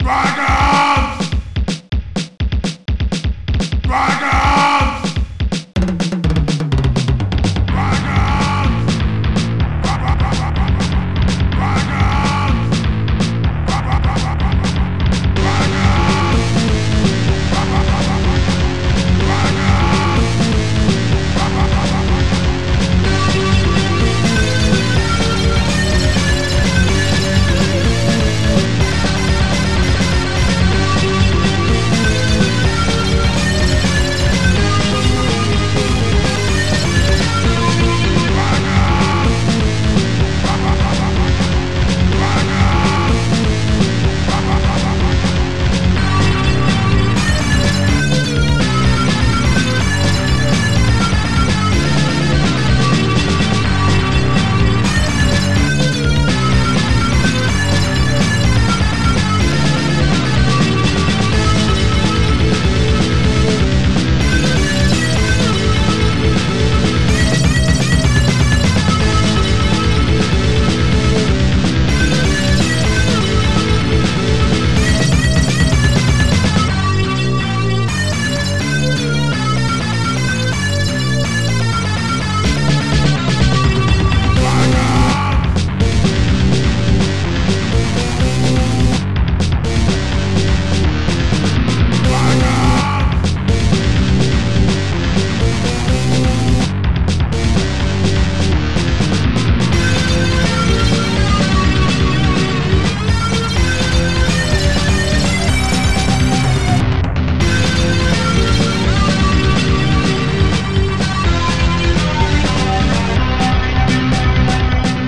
Tracker
ST-02:snare8 ST-89:leadguitar